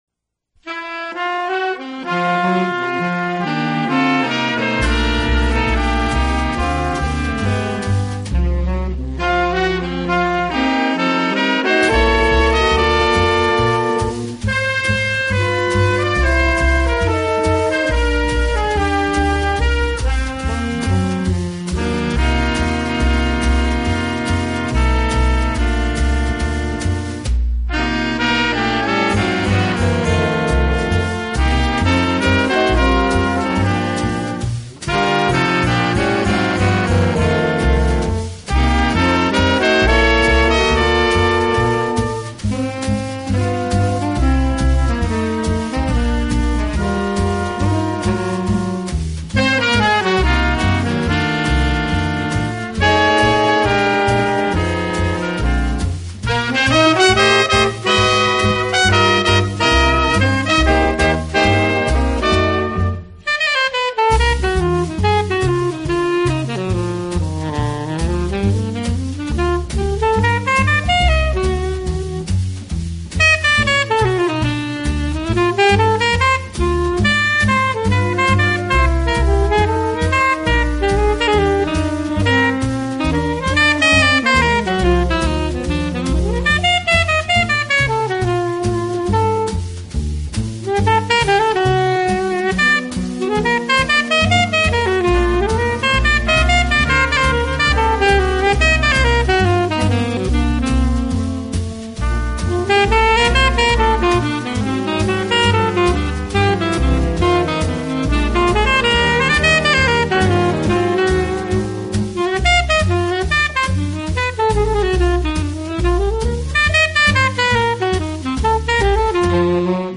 音乐类型：Jazz